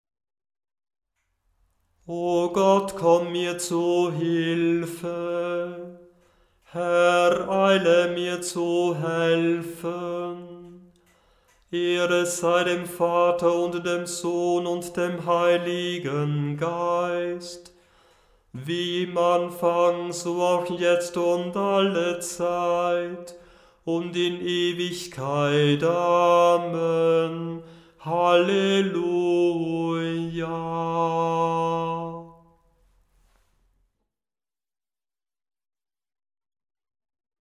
Beim Stundengebet übernimmt nach der Einleitung ein Kantor/eine Kantorin das Anstimmen der Gesänge.
Beim Stundengebet selber wird der Ruf natürlich nur einmal gesungen, die mehrmaligen Wiederholunge mögen das Üben erleichtern.
O Gott, komm mir zu Hilfe, Gl 627,1 914 KB Erster Ton f, eine große Sekunde tiefer O Gott, komm mir zu Hilfe, Gl 627,1 876 KB In der Fastenzeit ohne Hallelujaerster Ton g, wie notiert O Gott, komm mir zu Hilfe, Gl 627,1 1 MB In der Fastenzeit ohne Hallelujaerster Ton f, eine große Sekunde tiefer Lumen Christi, Gl 312,1 2 MB mit dreimaliger Steigerung